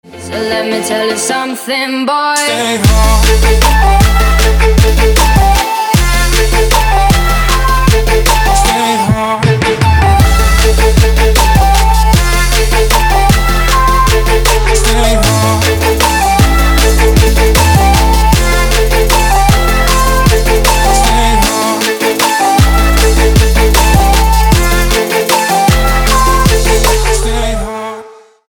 • Качество: 256, Stereo
dance
EDM
club